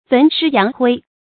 焚尸揚灰 注音： ㄈㄣˊ ㄕㄧ ㄧㄤˊ ㄏㄨㄟ 讀音讀法： 意思解釋： 見「焚骨揚灰」。